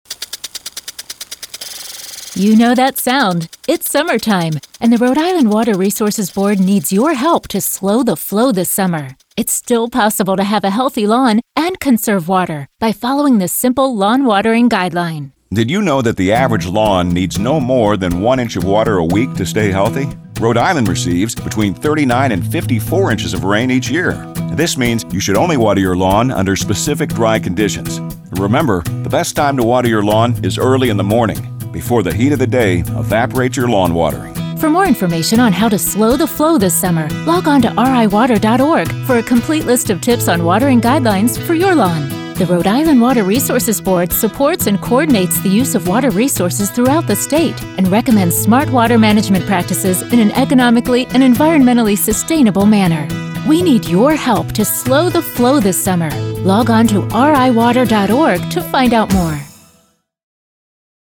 Have you heard us on the radio?
WRB_Radio_SlowTheFlow.mp3